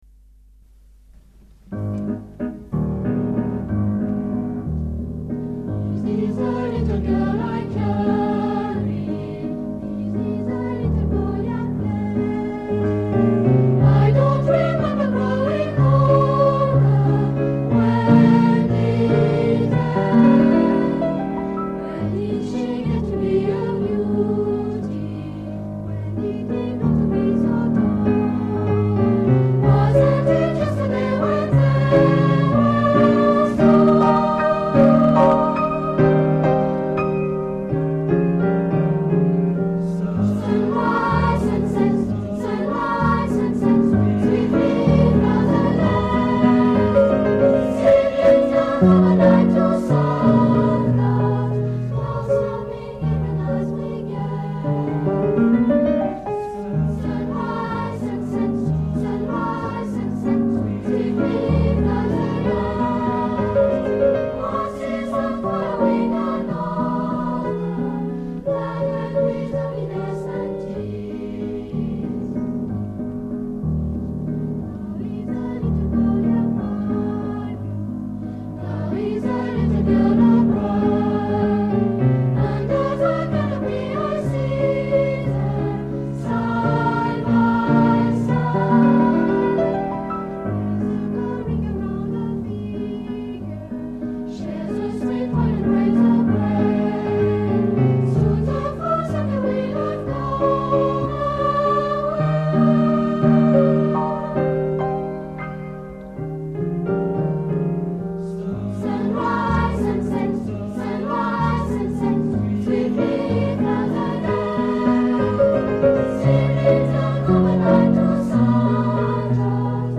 Concert  ACJ Macon CAC 20h30 27 Mai 1988 MACON